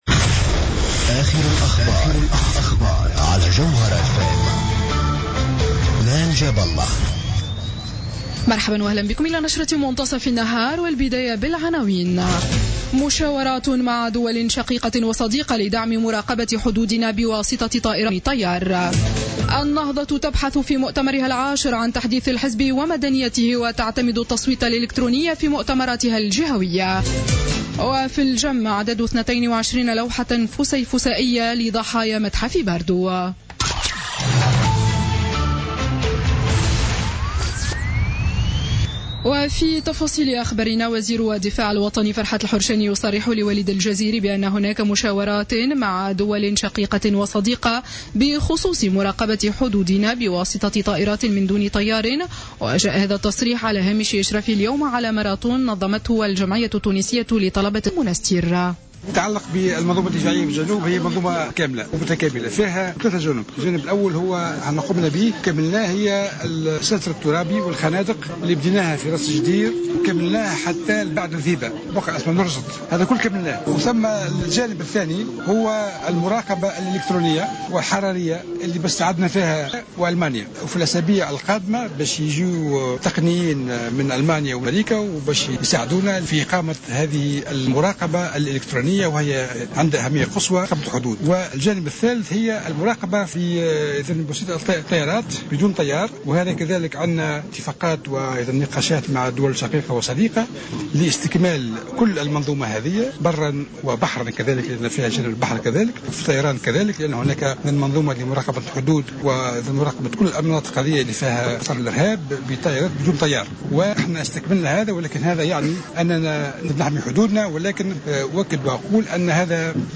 نشرة أخبار منتصف النهار ليوم الأحد 6 مارس 2016